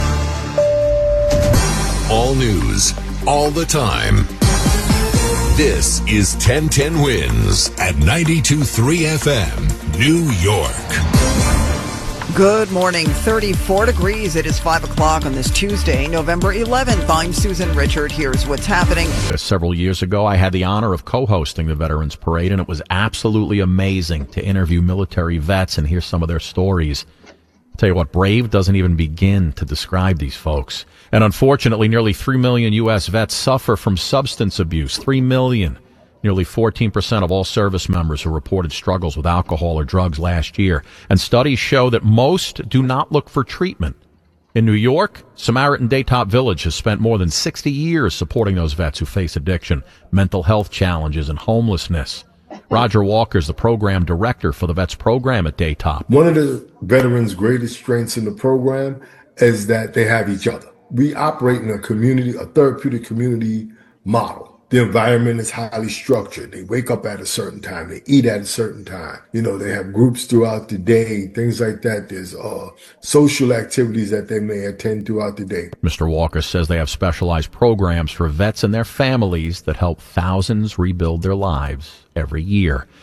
As aired live on 1010 WINS Radio, November 11, 2025